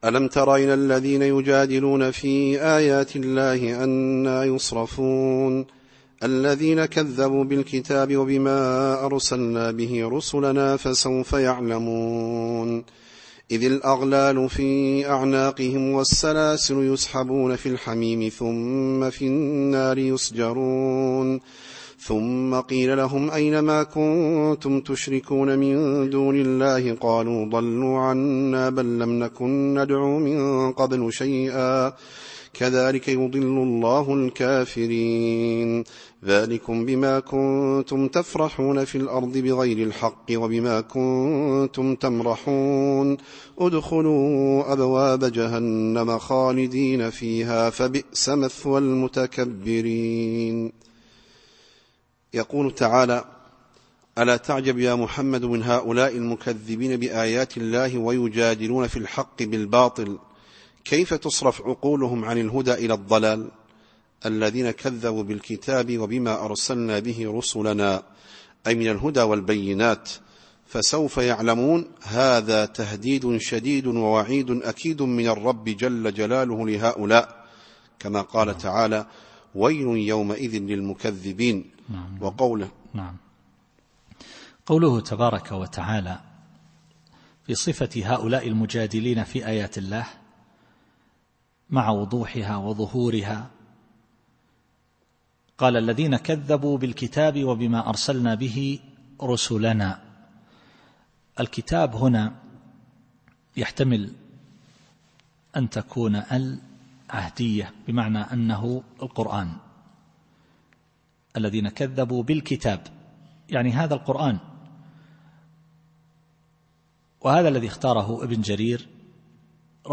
التفسير الصوتي [غافر / 69]